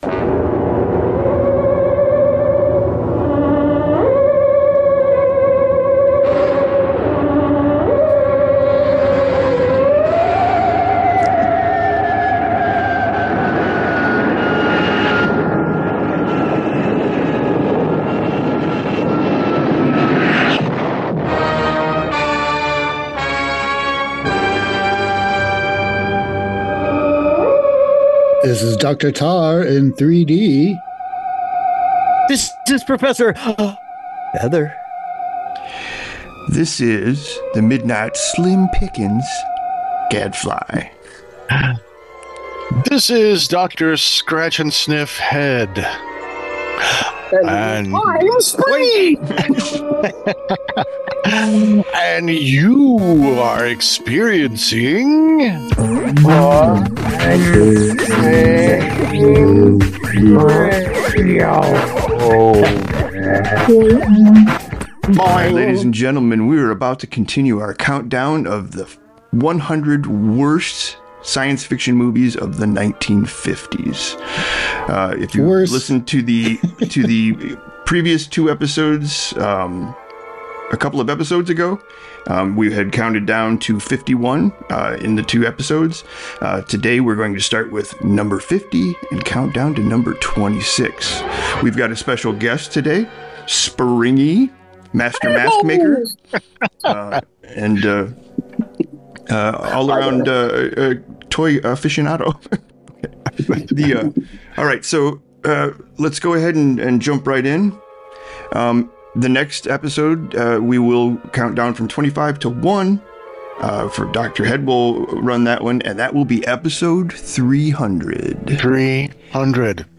Your hyperbolic hosts continue their countdown of the 100 worst sci-fi movies of the 1950’s. Oh boy, are they getting into the good ( very bad??) stuff.
Music Intro/Outro: 1950’s SciFi Theremin Music